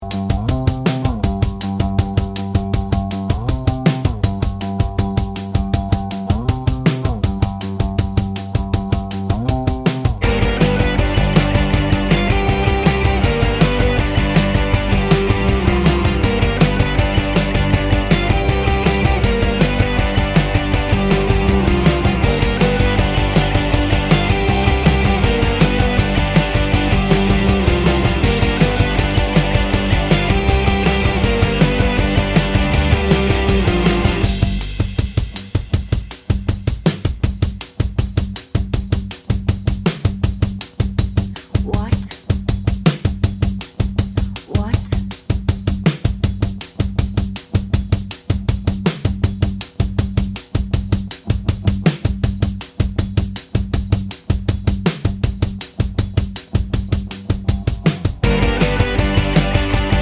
what Backing Vocals only Pop (2020s) 3:17 Buy £1.50